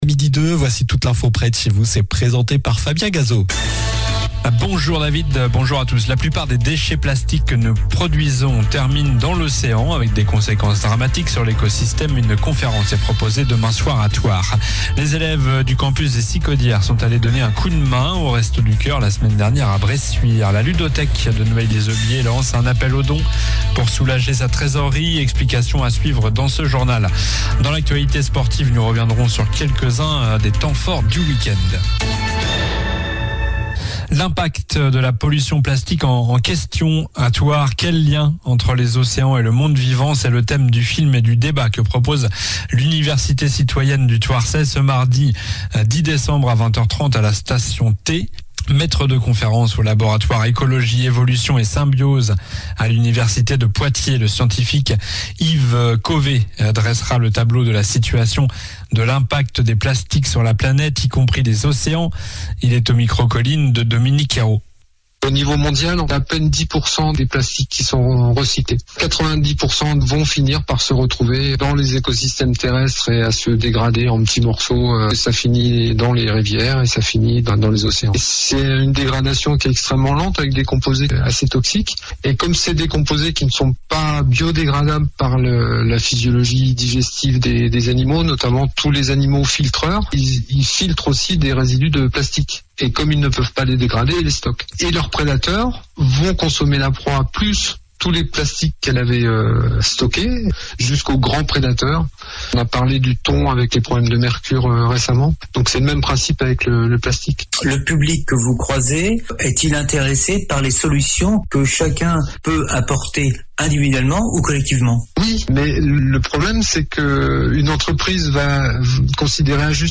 Journal du lundi 09 décembre (midi)